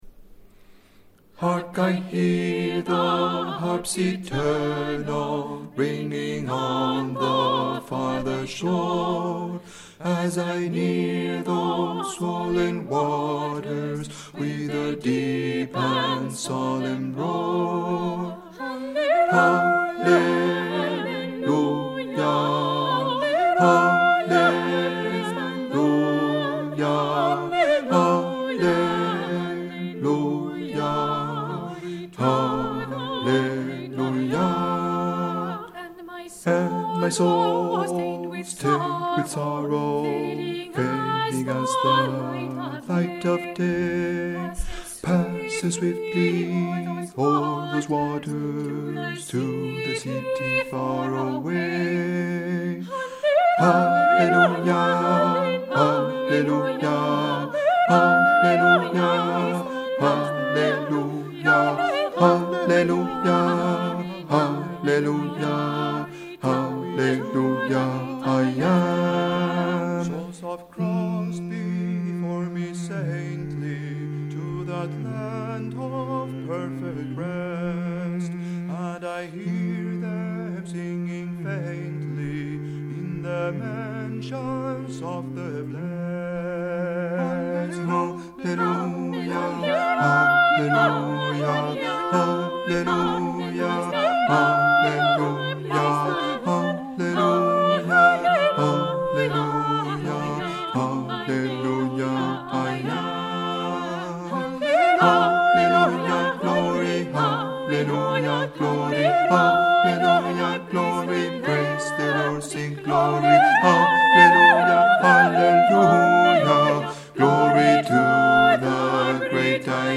B2 - U   (mp3) Traditional (arr: A. Parker) 2024 - 2025